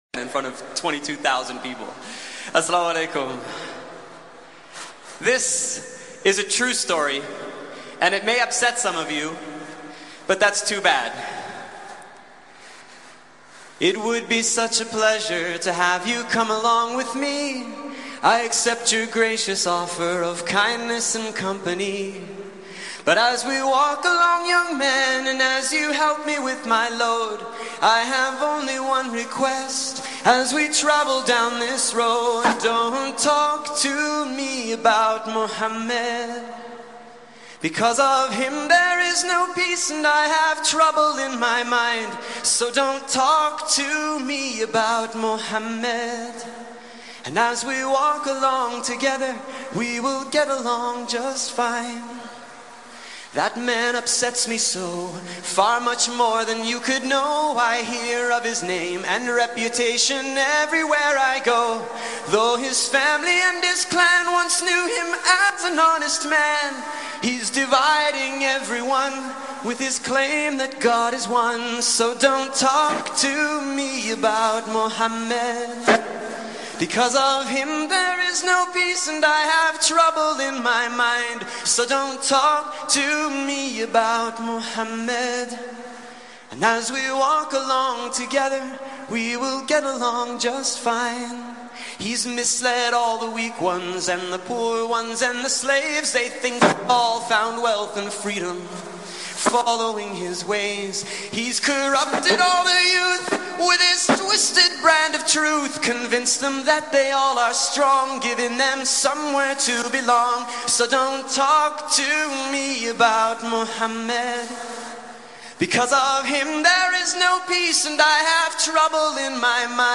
Nasheed About Muhammad (pbuh)